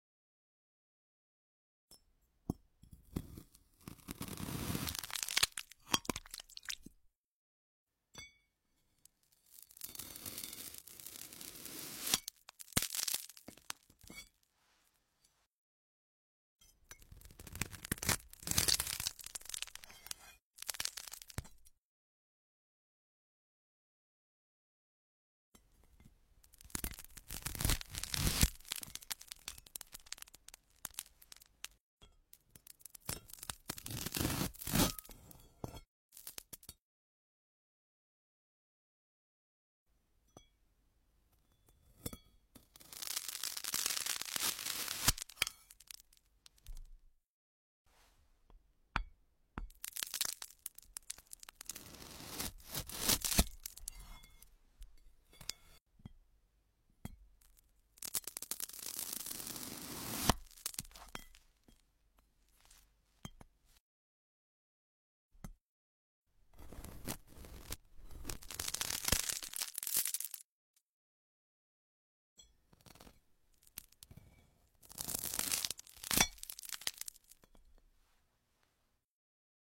Neon Glass Fruit Cutting AI sound effects free download
Neon Glass Fruit Cutting AI ASMR | Extremely Relaxing and Soothingly Crisp Neon Fruit Cutting Sounds